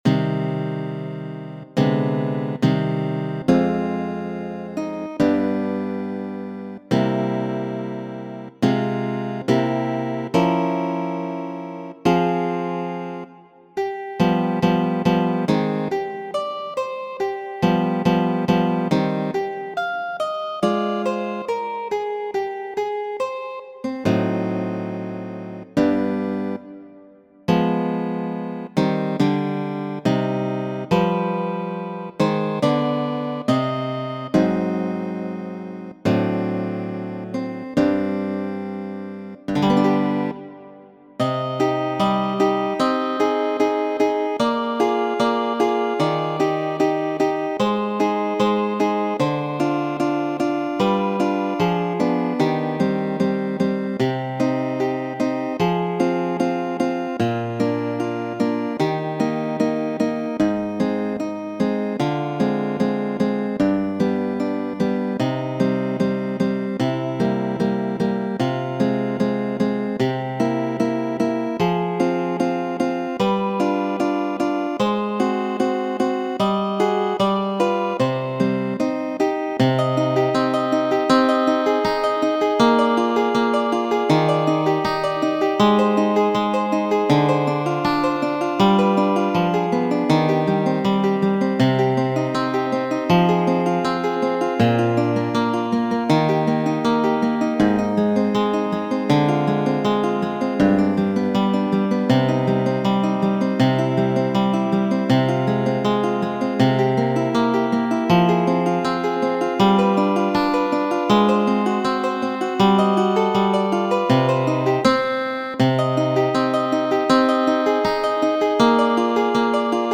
La muziko de Sor kaj tiu de Fortea ne estas samaj, almenaŭ unuavide, sed kiam oni aŭdas la pecon, oni eksentas la akordojn, la manieron konstrui harmonion, kiu estis karakteriza de la majstro de Barselona.